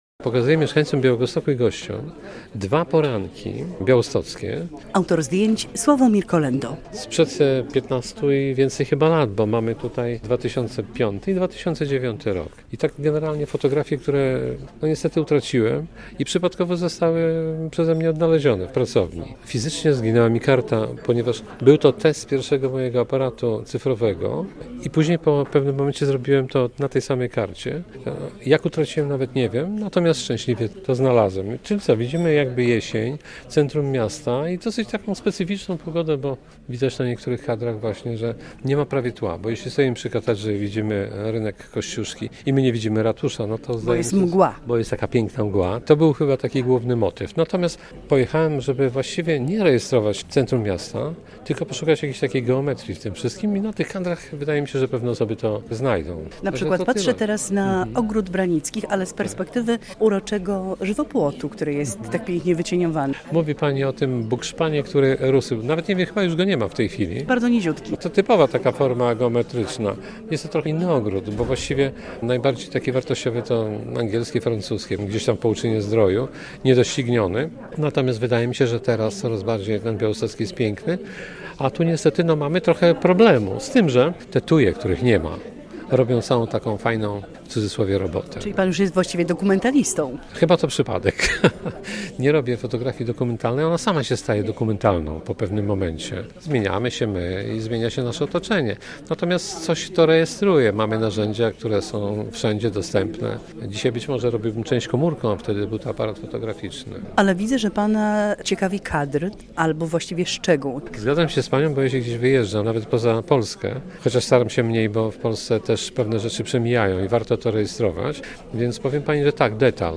Wystawa fotografii w Książnicy Podlaskiej - relacja